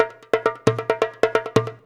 Index of /90_sSampleCDs/USB Soundscan vol.56 - Modern Percussion Loops [AKAI] 1CD/Partition C/17-DJEMBE133
133DJEMB04.wav